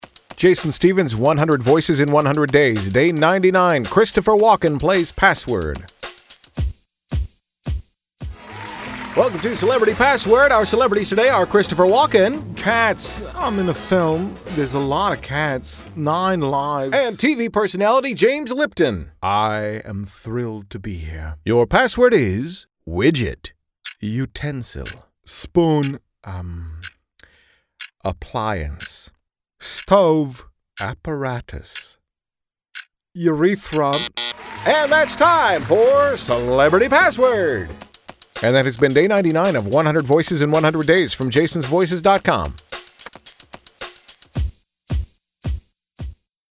My Christopher Walken impression gets Day 99 of the 100 Voices in 100 Days project.
Some projects call for a spot-on impression, but most are parody.
Tags: celebrity impersonations, Christopher Walken impression, voice matching